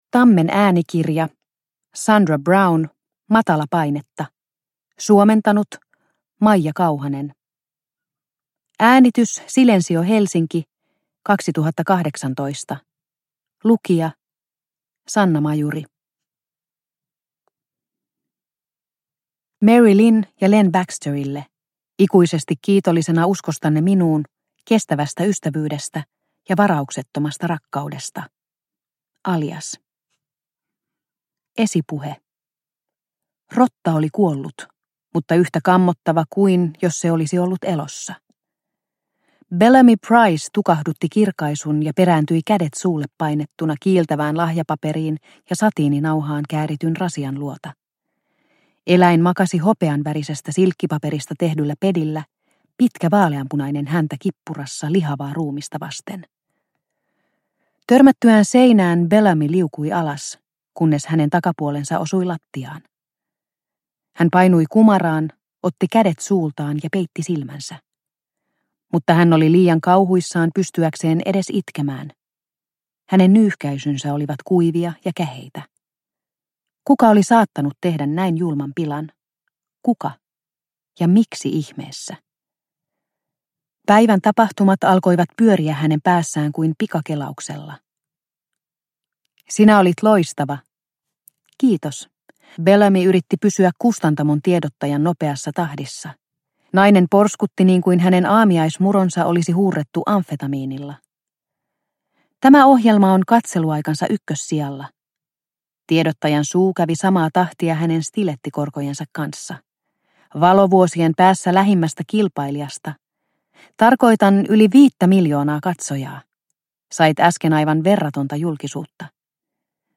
Matalapainetta – Ljudbok – Laddas ner